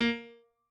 pianoadrib1_37.ogg